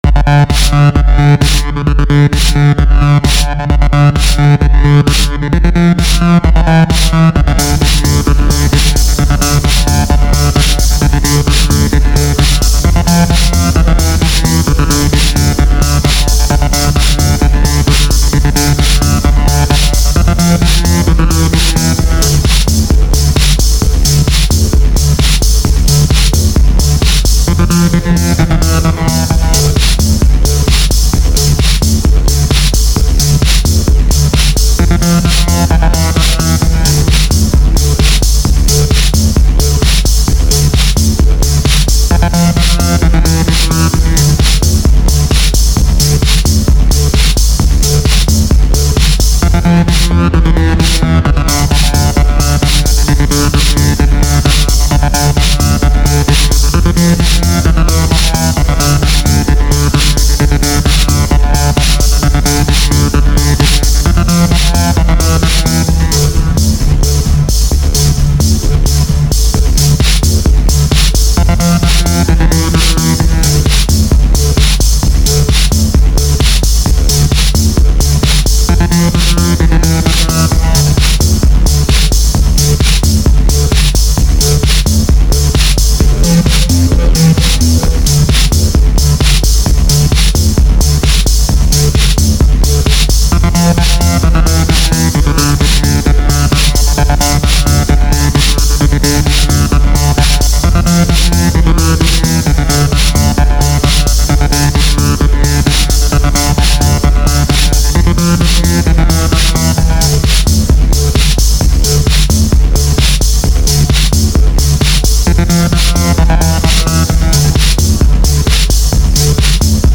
만들고나니 힙합은 아닌것같네요......다양한 스타일을 시도해보고 있습니다.